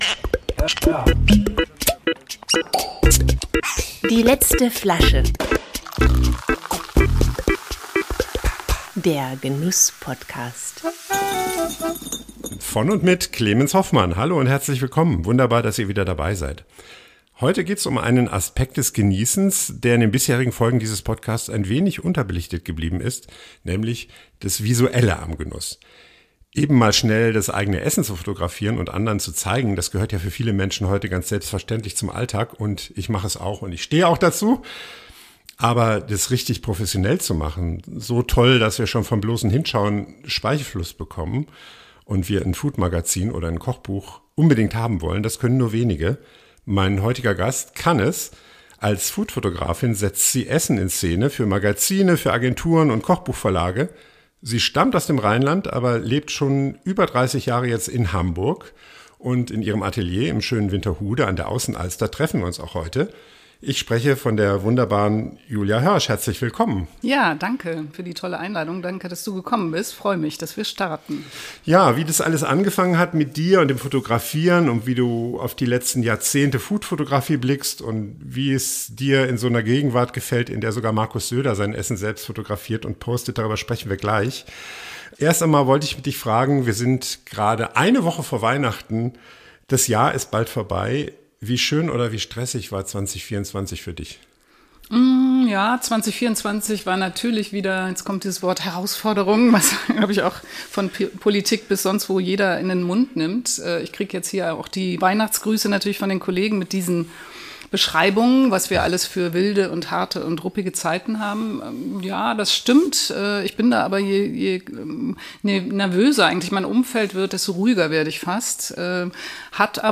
Ein sehr persönliches Gespräch zum Jahresausklang!